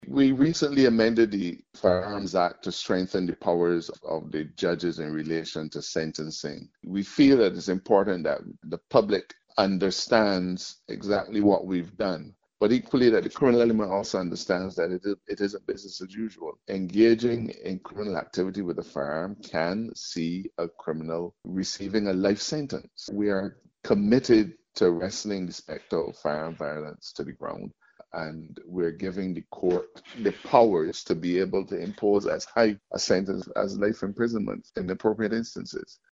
Attorney General Dale Marshall.